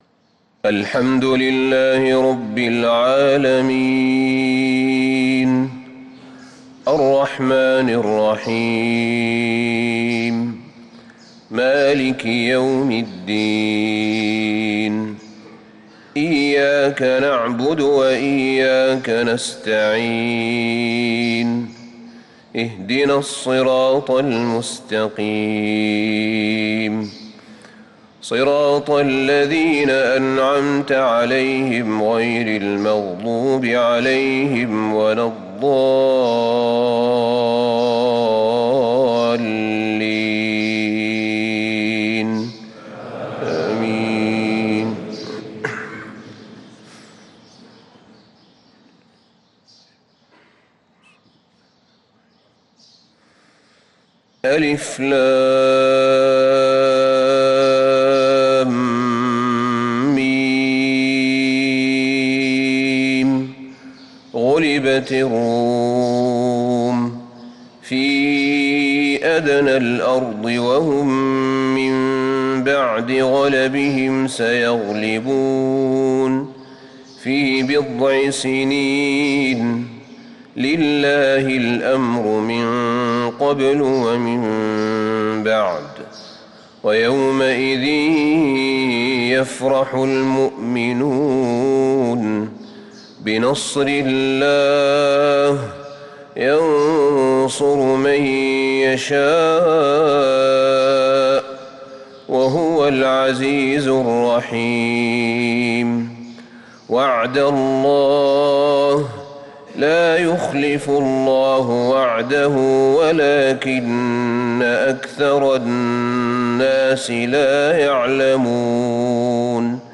صلاة الفجر للقارئ أحمد بن طالب حميد 2 رجب 1445 هـ
تِلَاوَات الْحَرَمَيْن .